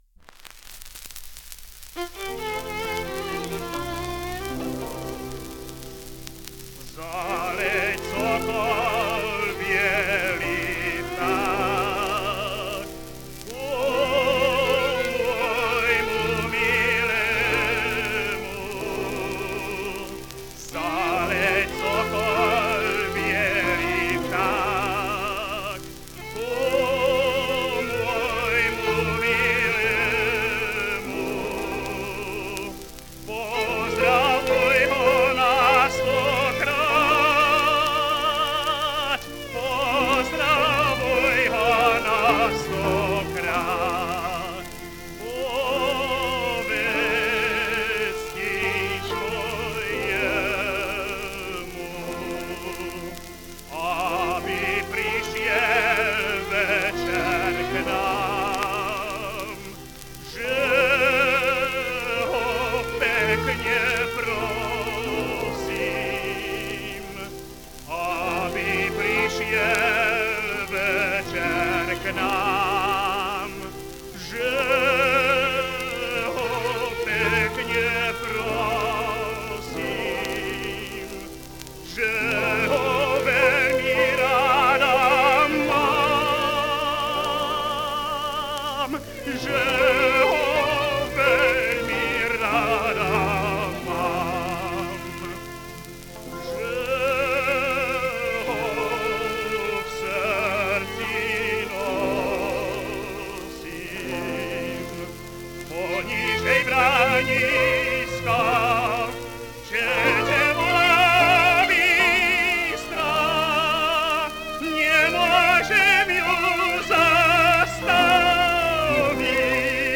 Smes slov. ľudových piesní (podnázov)
Dátum a miesto nahrávania: 28.4.1937, Studio Rokoska, Praha
Zaleť sokol biely vták / Štefan Hoza Názov Zaleť sokol biely vták (hlavný názov) Smes slov. ľudových piesní (podnázov) Dátum a miesto nahrávania: 28.4.1937, Studio Rokoska, Praha Popis Mužský spev so sprievodom cigánskej skupiny. Autori a skladatelia slovenské ľudové piesne Účinkujúci Štefan Hoza